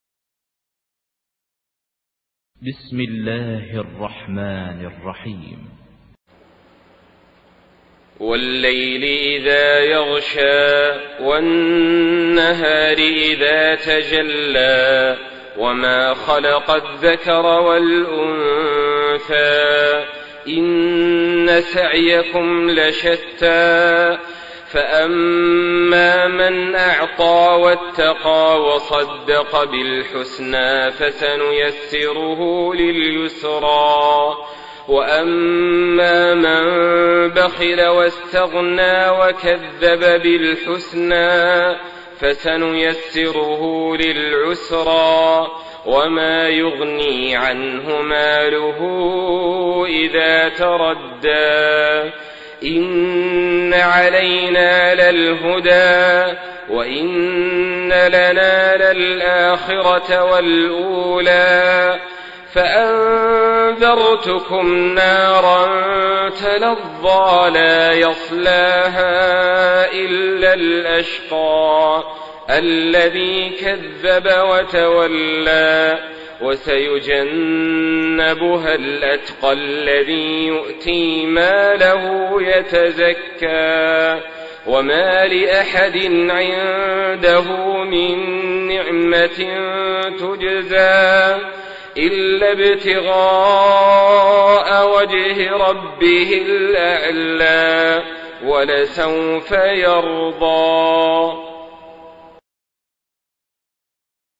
Surah Leyl MP3 by Saleh Al-Talib in Hafs An Asim narration.
Murattal